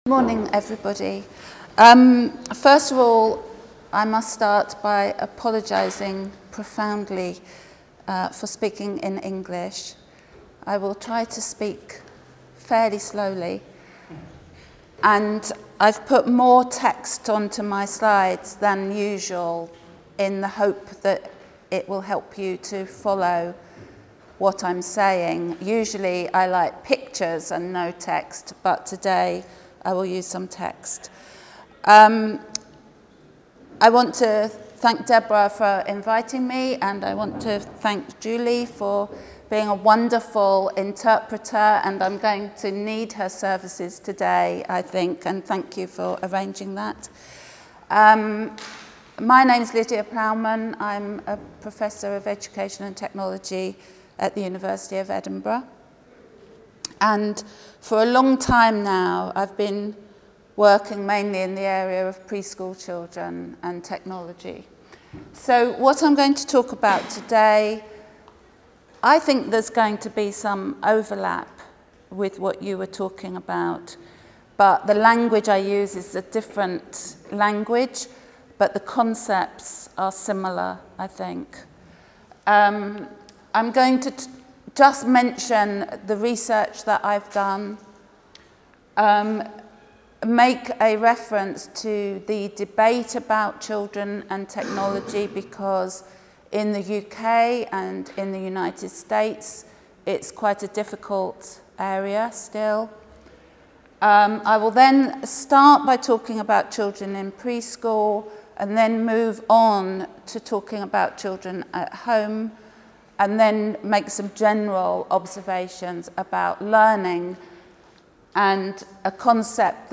Journée d’étude organisée par l’EHESS en collaboration avec le ministère de l’éducation nationale 8 avril 2014, Salle des Conférences, Lycée Henri IV, 75005 Paris Maintenant, les objets communicants font partie de l’environnement des enfants dès leur naissance. Si ces objets ne sont pas entre les mains des jeunes enfants, ils sont omniprésents chez leurs entourages et dans le monde matériel et culturel qui les entoure.